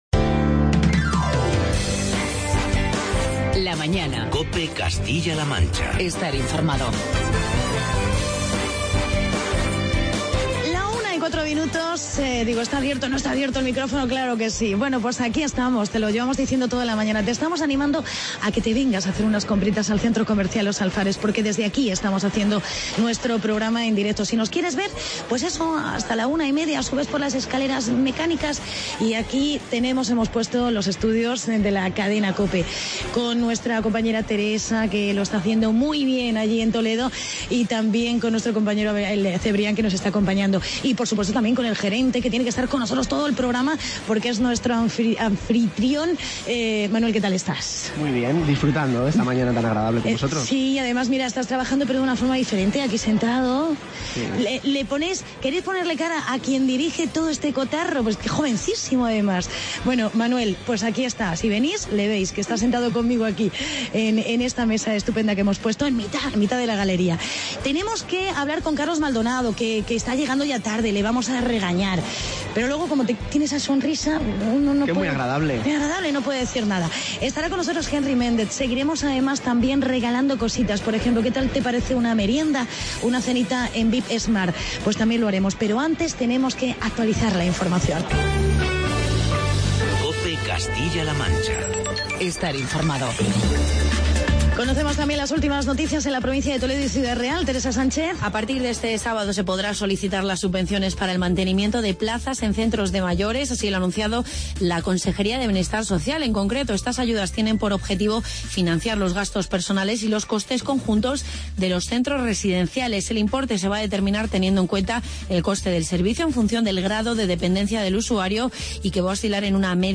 Seguimos con el programa especial desde el Centro Comercial Los Alfares de Talavera de la Reina.